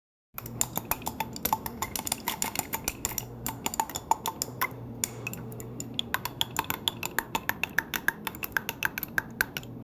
Stirring slimy batter sizzle simmering into pot scrape 0:15 Created Nov 7, 2024 3:07 AM place batter tup in oven 0:03 Created Mar 30, 2025 10:21 AM Mixing batter in a warm kitchen with soft whisking, flour puffs, clinking spoons, and playful kitchen sounds." 0:10 Created May 31, 2025 5:06 PM
mixing-batter-in-a-warm-5aled266.wav